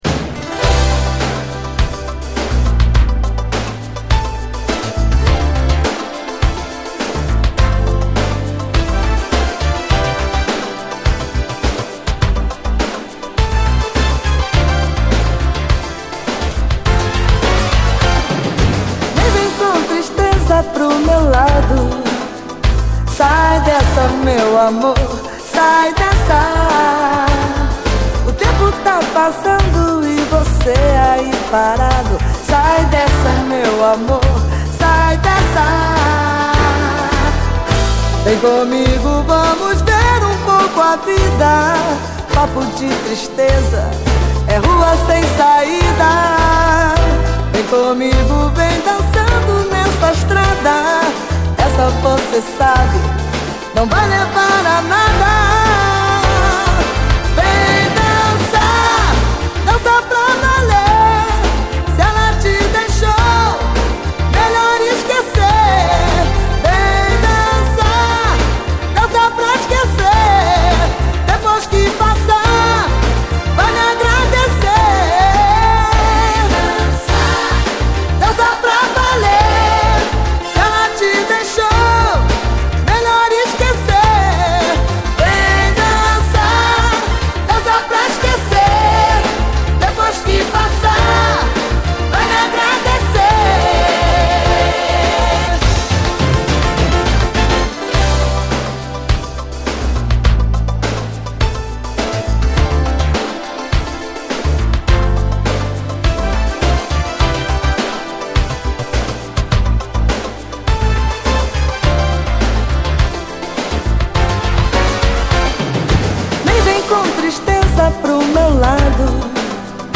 トロピカルなシティー・ポップ・フレーヴァーを乗せた爽快ブラジリアン・ソウル